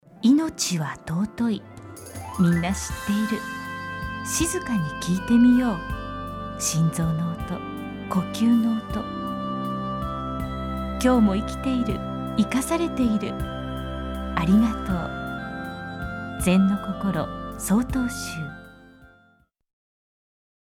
ラジオ放送コマーシャル（mp3ファイル）